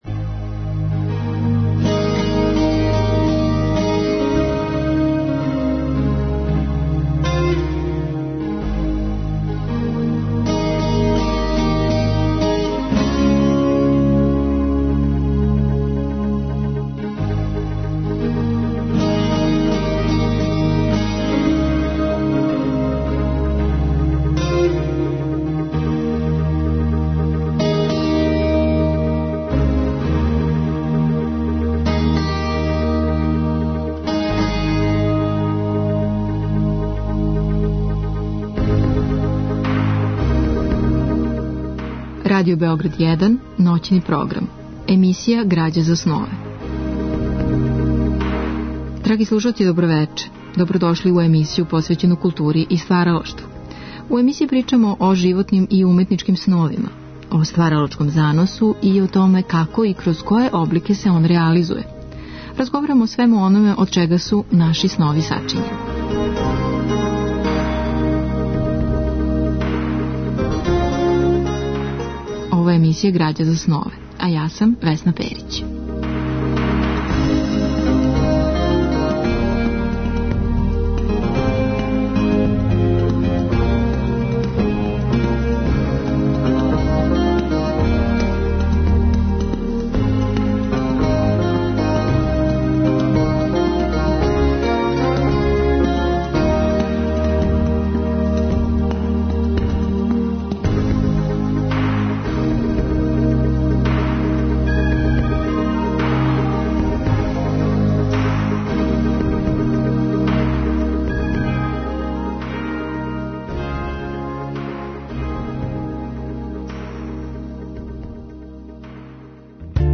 Разговор и добра музика требало би да кроз ову емисију и сами постану грађа за снове.
У другом делу емисије слушаћемо одабране делове радио драматизација дела писаца који су имали утицаја на стваралаштво наше гошће - Оскара Вајлда, Артура Рембоа, Кафке, Чехова, као и радио драма посвећене пионирима балканске кинематографије као и Холивуда.